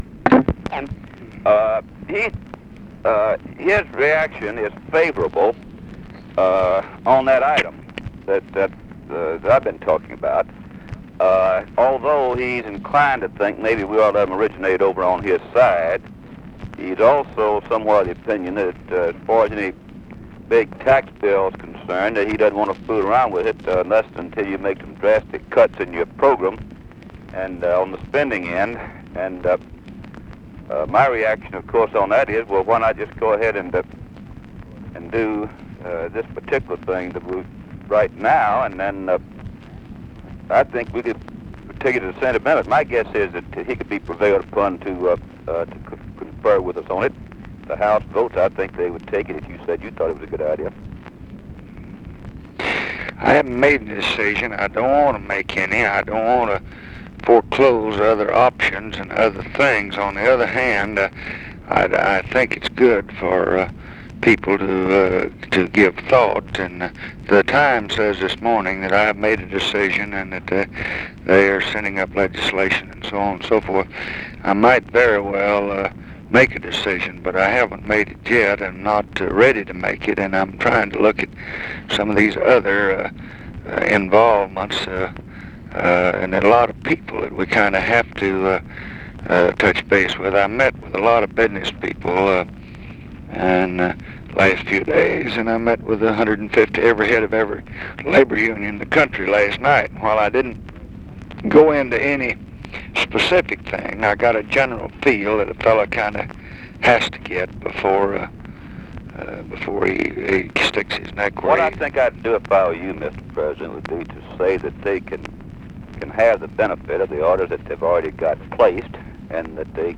Conversation with RUSSELL LONG, September 1, 1966
Secret White House Tapes